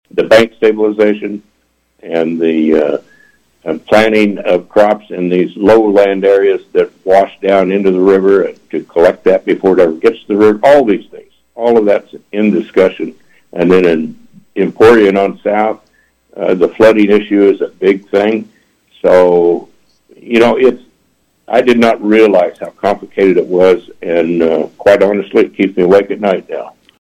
On KVOE’s Morning Show on Wednesday, 51st District Representative Ron Highland of Wamego said the combination of tax revenue and COVID relief funding from the federal government has the state with a $ 2 billion ending balance now and possibly up to $3 billion by July, along with over $1 billion in unallocated COVID funds.